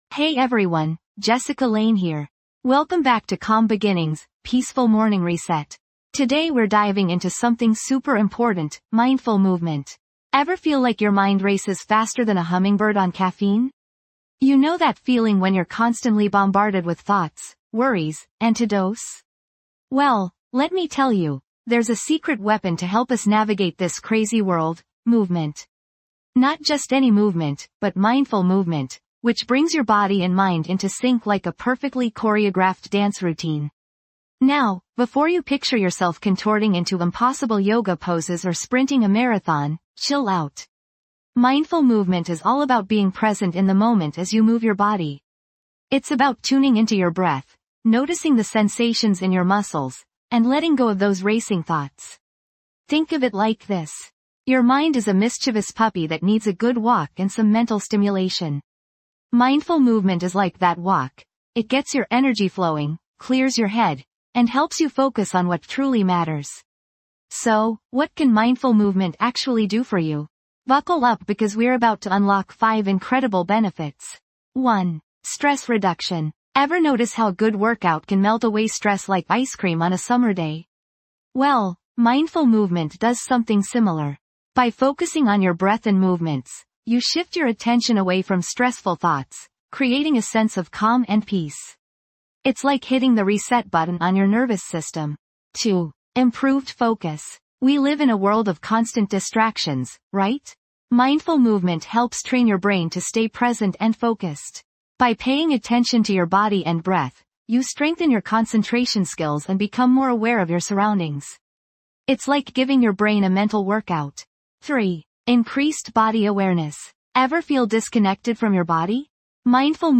Calm Beginnings | Peaceful Morning Reset guides you through a soothing journey of mindfulness and relaxation to gently awaken your mind and body. Each episode offers calming guided meditations, gentle affirmations, and peaceful soundscapes designed to melt away stress and set the tone for a positive and productive day.